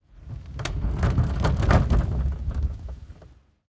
Wheel_wood.ogg